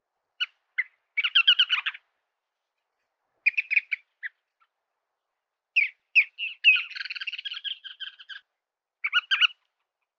「日本の鳥百科」キョウジョシギの紹介です（鳴き声あり）。